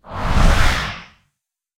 PixelPerfectionCE/assets/minecraft/sounds/mob/enderdragon/wings6.ogg at mc116
wings6.ogg